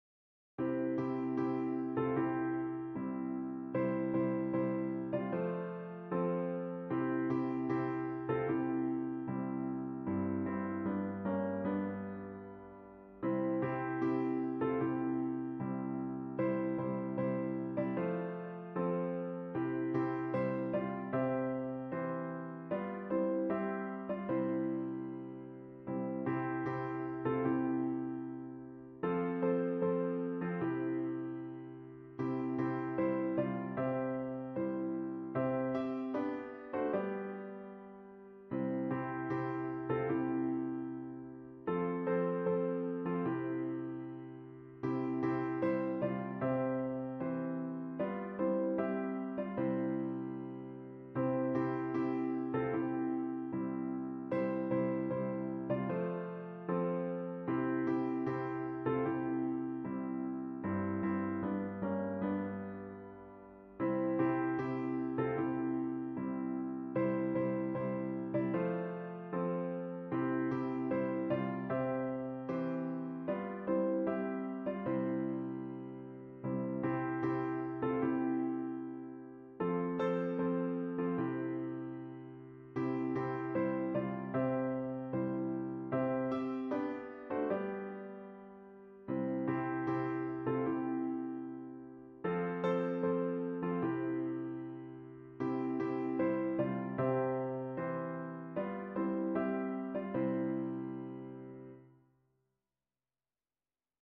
A traditional thanksgiving hymn
for piano